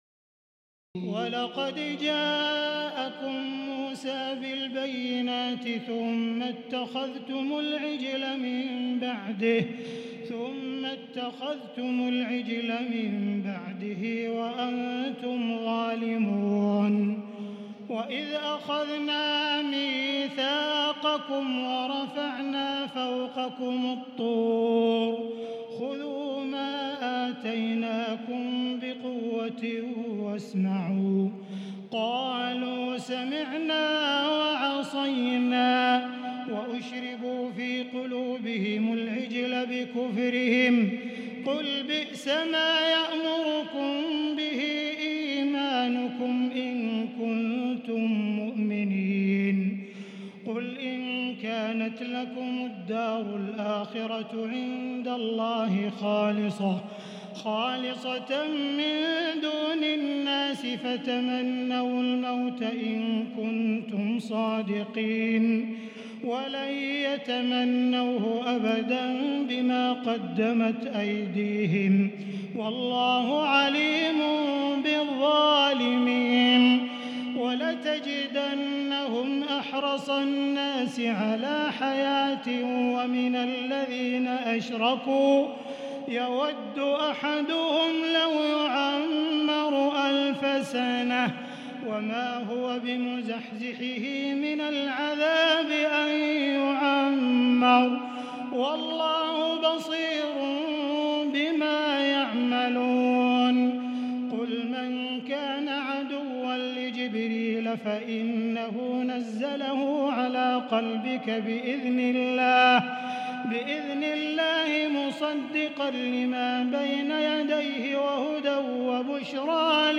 تراويح الليلة الأولى رمضان 1438هـ من سورة البقرة (92-157) Taraweeh 1st night Ramadan 1438H from Surah Al-Baqara > تراويح الحرم المكي عام 1438 🕋 > التراويح - تلاوات الحرمين